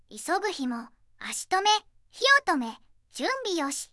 標語を生成音声で聞く（3秒）